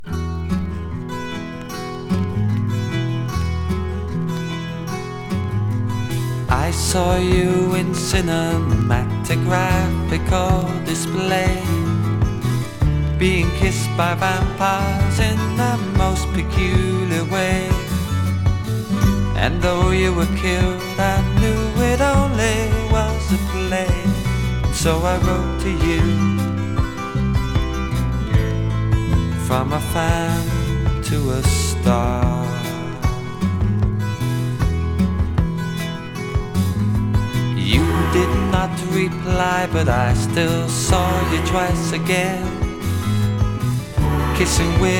SideAでは英国の香りが漂い、ドラマチックさたっぷり。SideBはポップさとスワンプ感も。
Rock, Pop　USA　12inchレコード　33rpm　Stereo